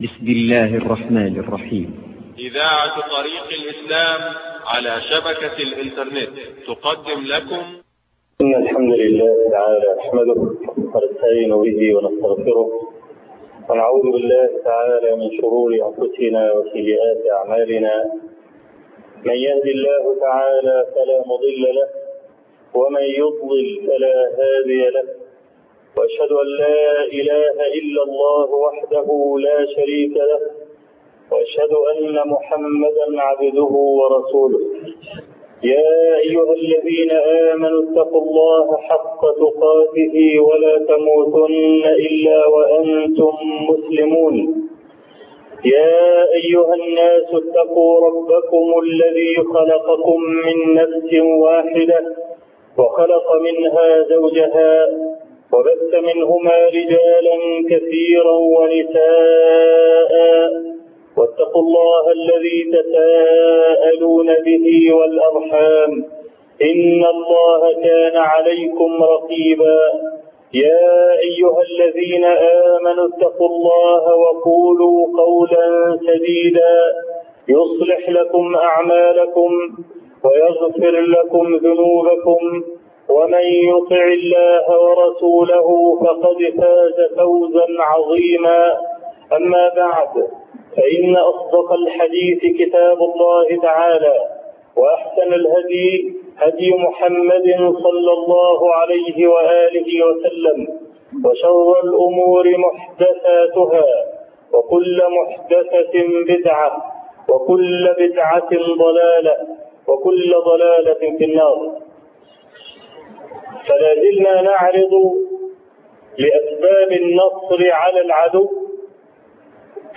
سلسلة غزوة أحد[5] الربا 2 خطبة الجمعة - الشيخ أبو إسحاق الحويني - الطريق إلى الله
خطبة الجمعة